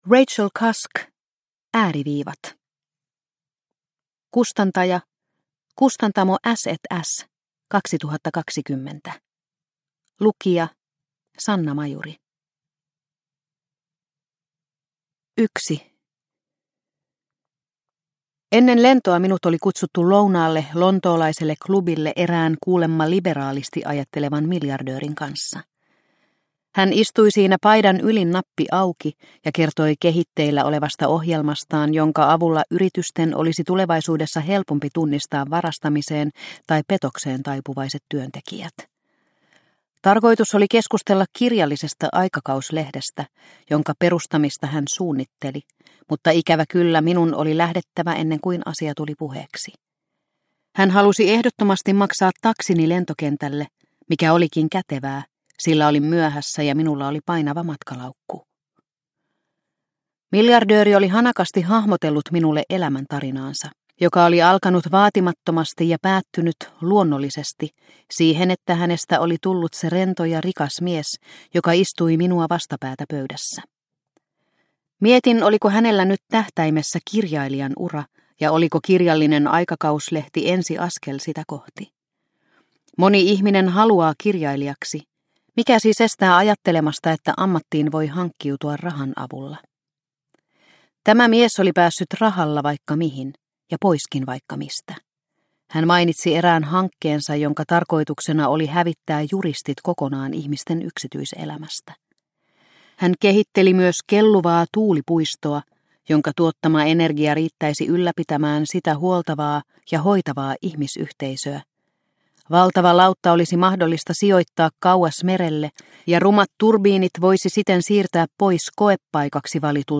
Ääriviivat – Ljudbok – Laddas ner